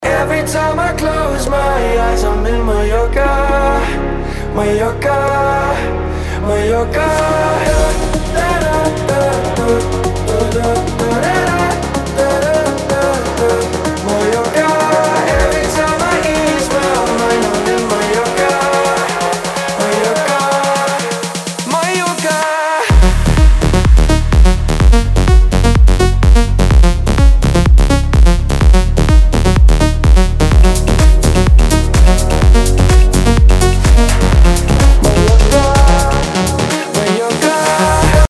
EDM music